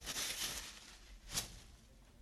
2013年 2014年 " Sac poubelle
描述：这个声音是在大学的厕所里录制的。这是一个人在垃圾袋降到7分贝后唰唰作响的声音
Tag: 垃圾袋 维护 沙沙 大胆